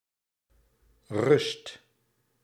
Ääntäminen
IPA : /ɹɛst/